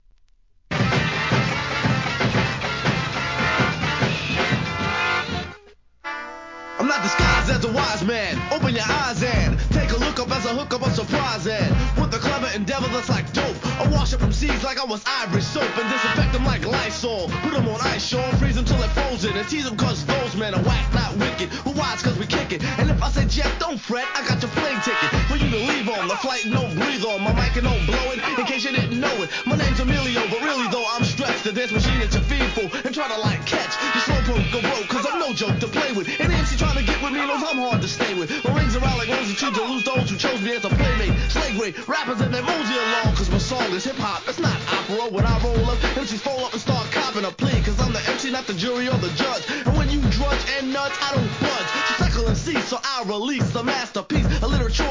HIP HOP/R&B
1989年ミドルスクール!!!